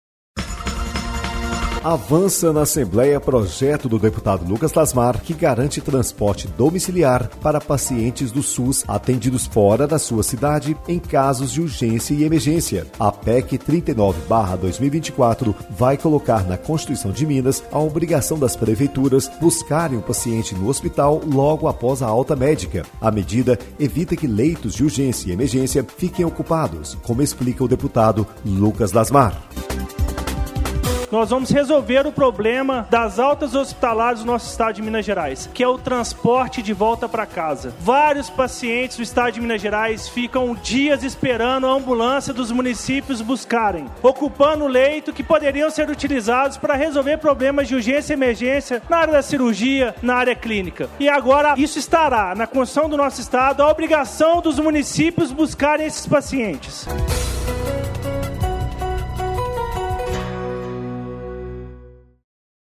Boletim de Rádio